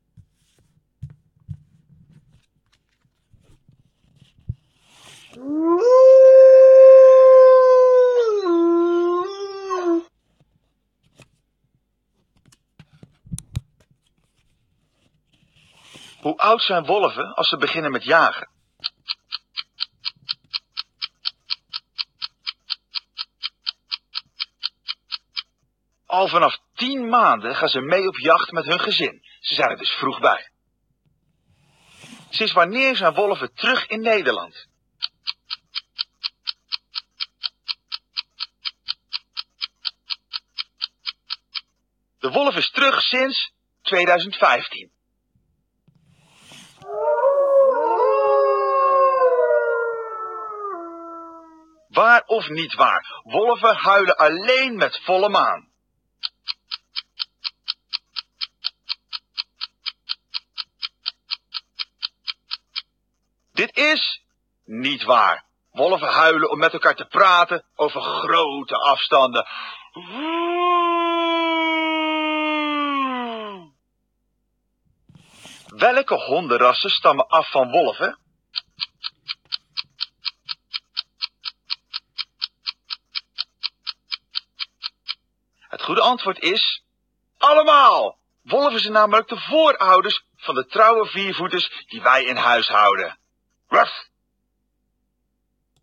AH-dierengeluiden-testrecording.ogg